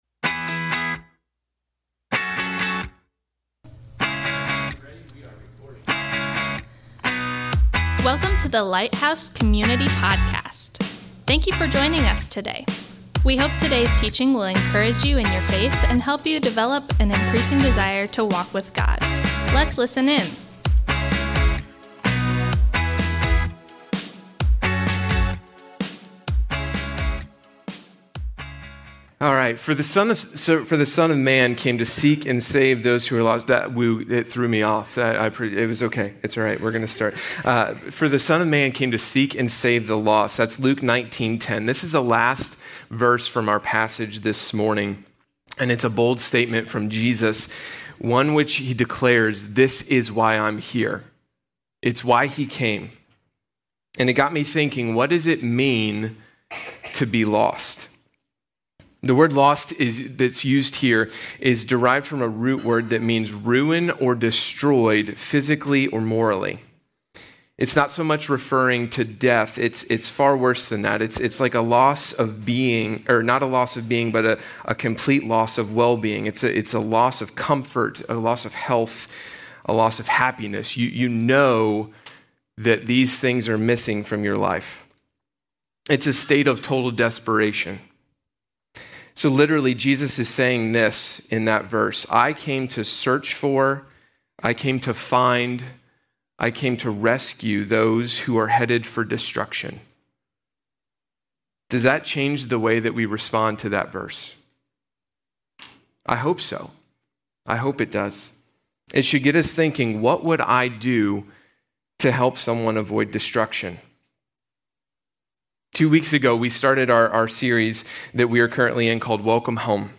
We are starting a new teaching series today called "Welcome Home". This is a unique topical series where we are providing a training on kindness. We will be looking at hospitality in all areas of ministry life.